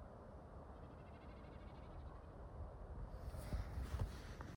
Fuglelyd?
Lyden gjentok seg hele tiden og «fulgte» etter oss ovenfra, men vi kunne ikke se tegn til noen fugl.
Hører svakt lyden fra fluktspillet til enkeltbekkasin her. Det er de ytre stjertfjærene som vibrerer og lager denne lyden.